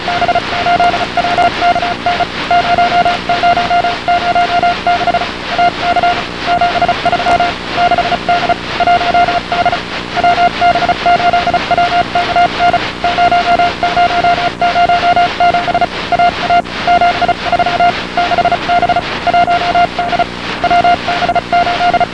Weak TV pictures and audio telemetry were received a short time after the anticipated payload ejection.
(Each telemetry frame is approx. 10 seconds)